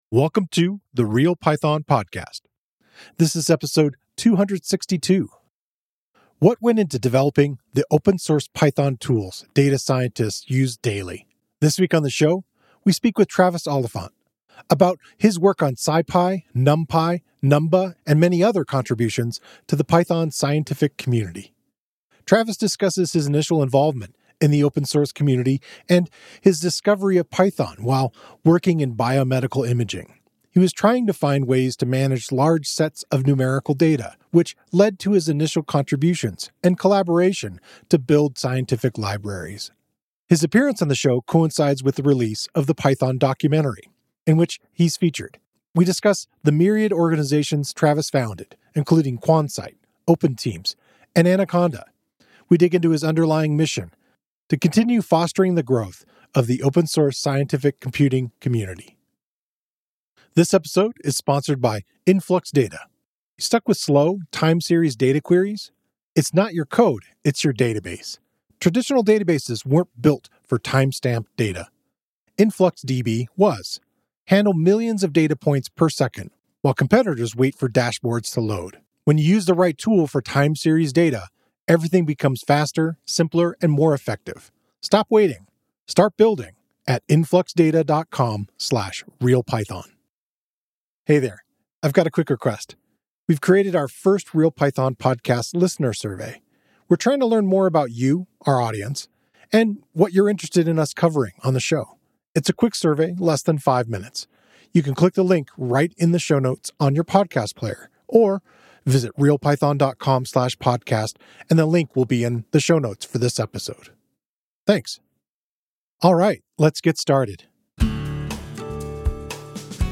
What went into developing the open-source Python tools data scientists use every day? This week on the show, we talk with Travis Oliphant about his work on SciPy, NumPy, Numba, and many other contributions to the Python scientific community.